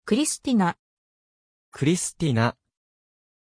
Pronunciation of Krystina
pronunciation-krystina-ja.mp3